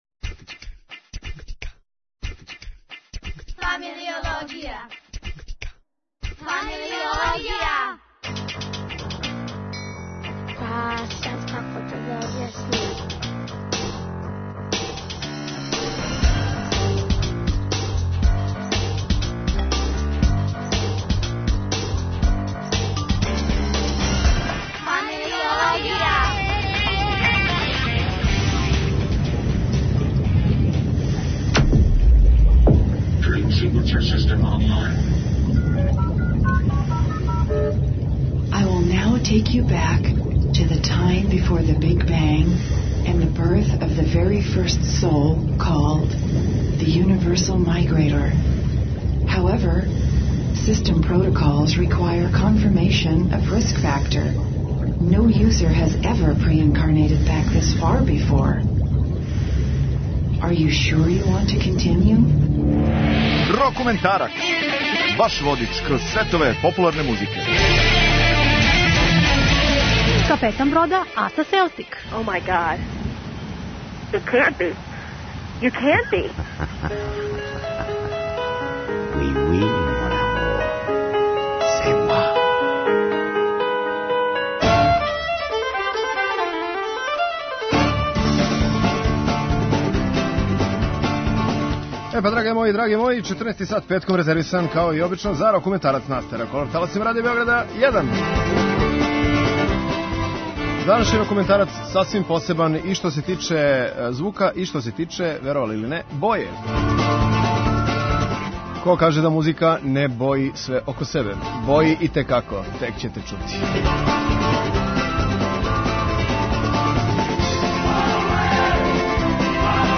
Добро, нећемо баш свирати уживо, али ћемо заједно слушати најбоље песме из пребогатог ирског музичког наслеђа, а из којих је свакако рођен и рокенрол.